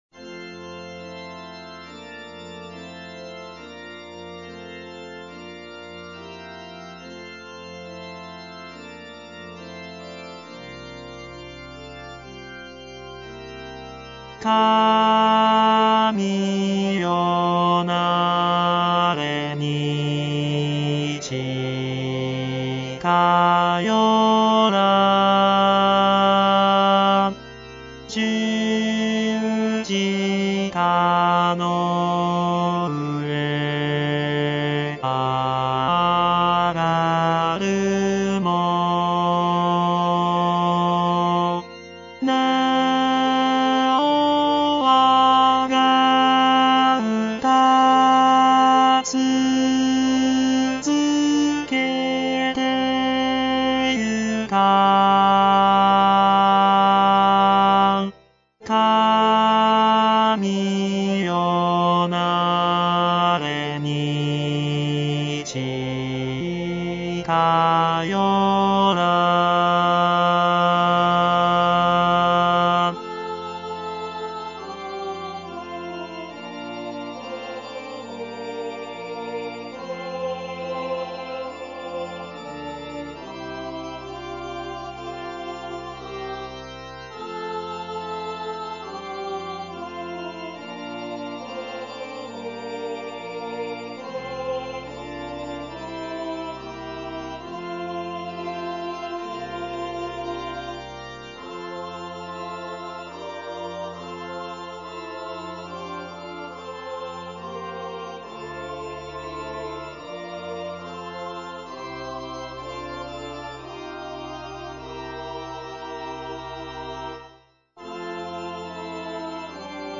アルト2（フレットレスバス音）